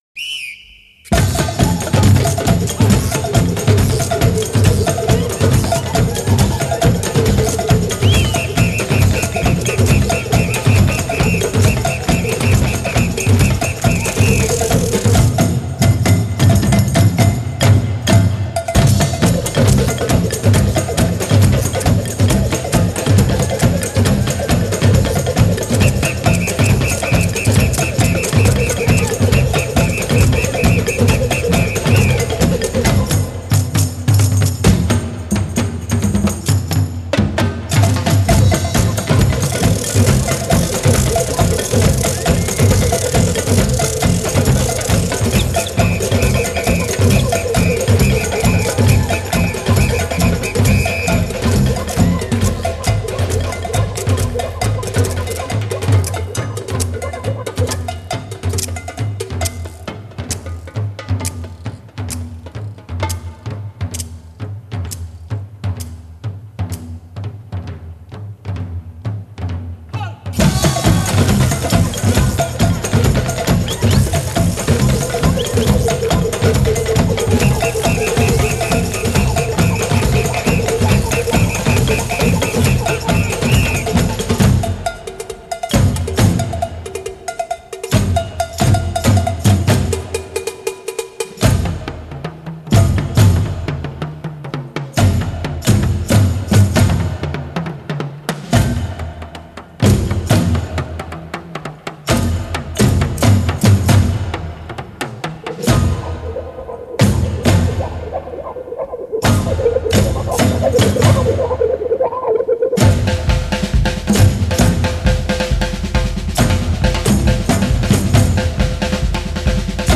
08 Samba